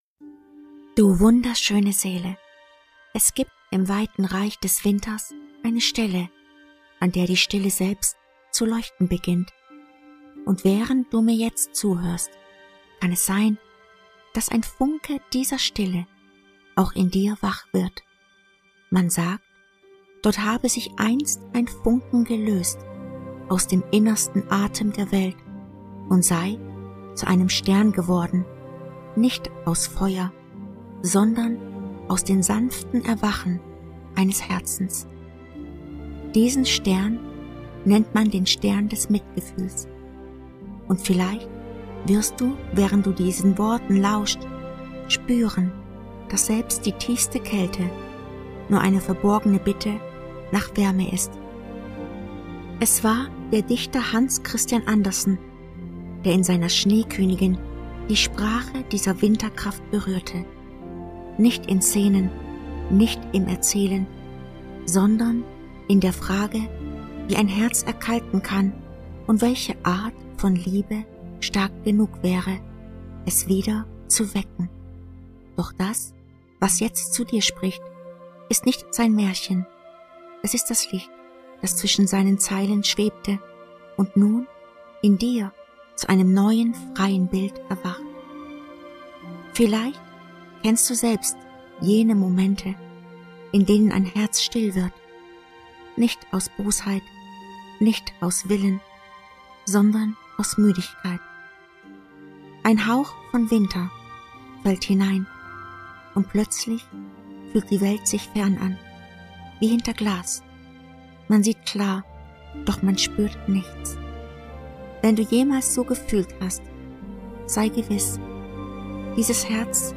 Majestätisch. Sanft.